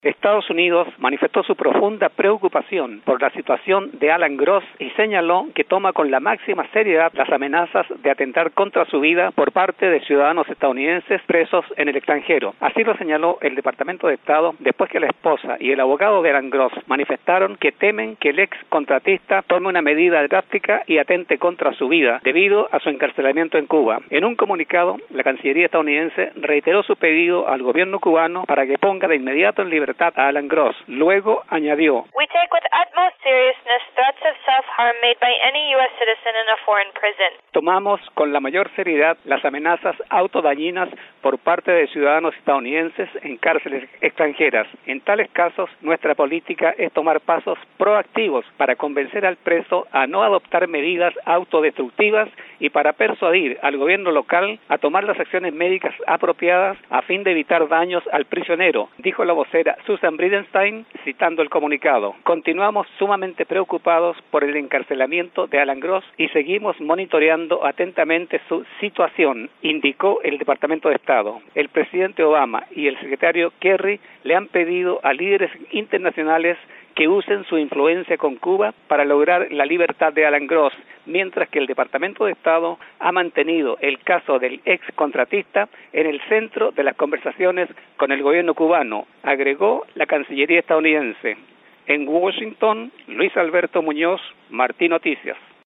tiene las declaraciones de una portavoz de la cancillería estadounidense